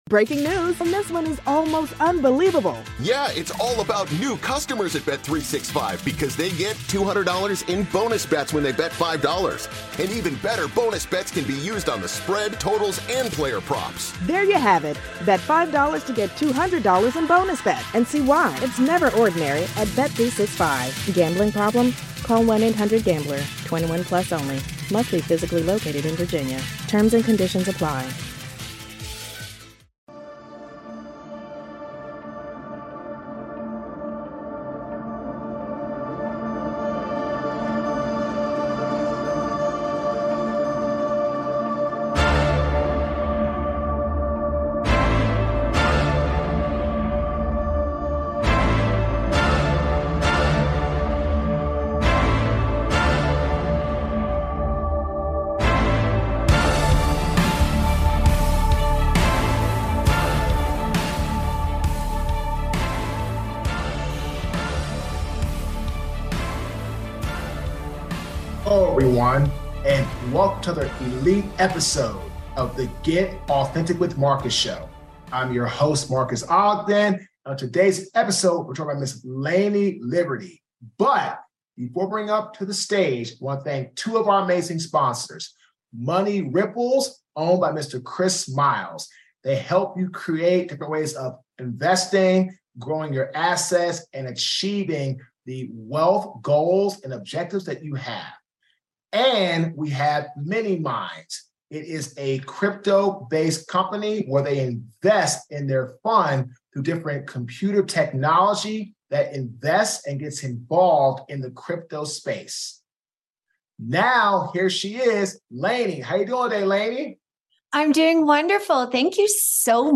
In this authentic conversation